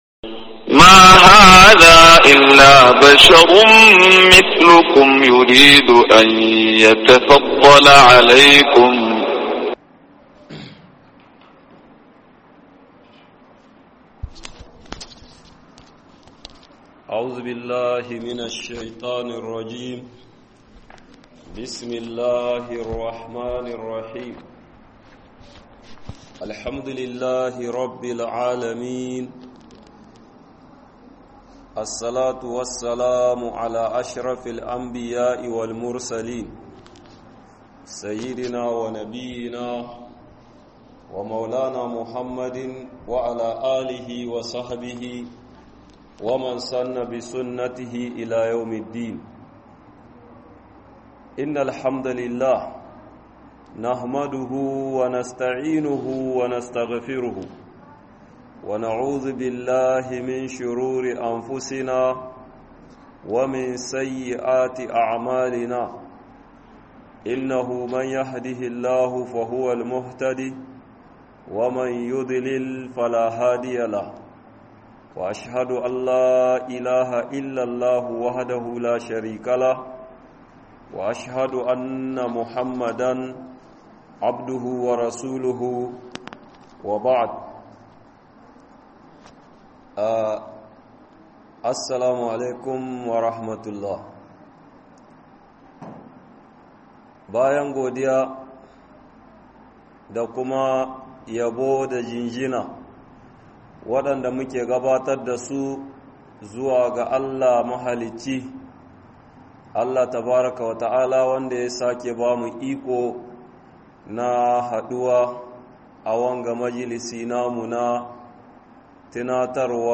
dalilan samun albarka - MUHADARA